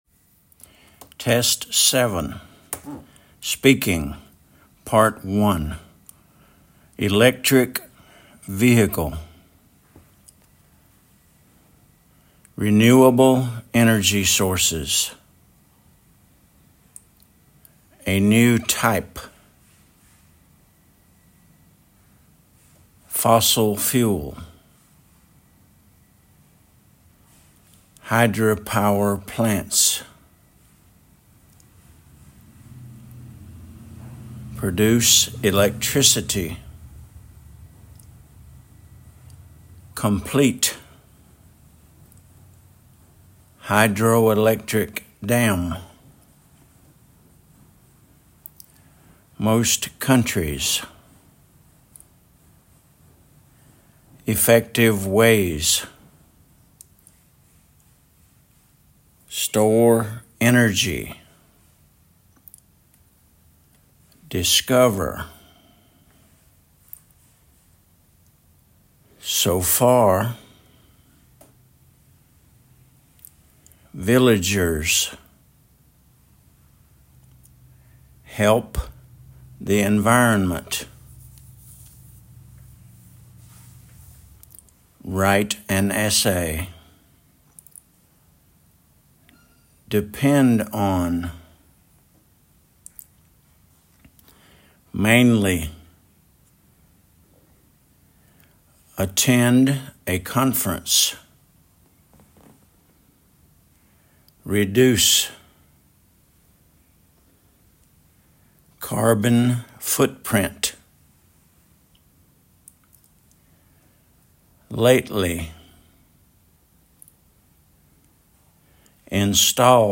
electric vehicle /ɪˈlɛktrɪk ˈviːhɪkl/
hydroelectric dam /ˌhaɪdrəʊɪˈlɛktrɪk dæm/
solar panels /ˈsəʊlə ˈpænəlz/